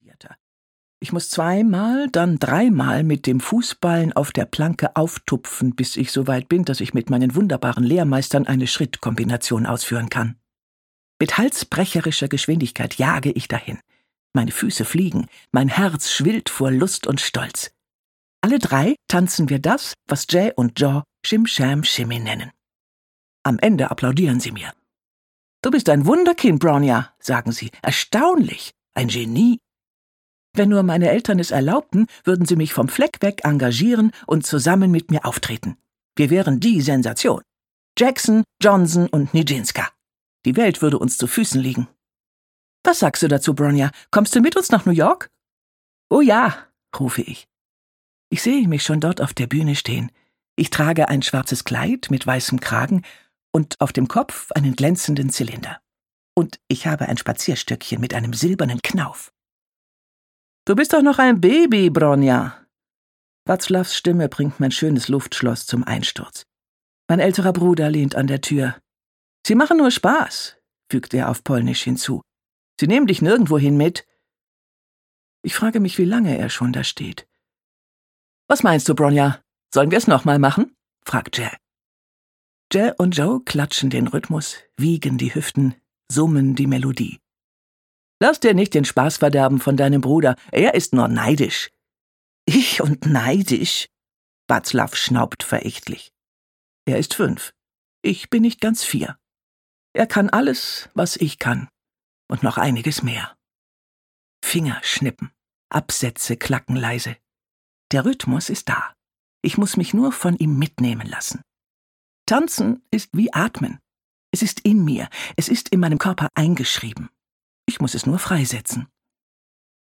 Hörbuch Die Schwester des Tänzers, Eva Stachniak.